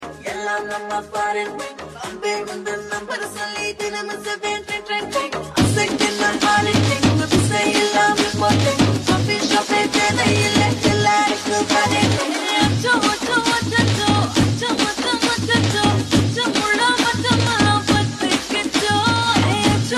Tamil Ringtones